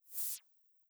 Minimize1.wav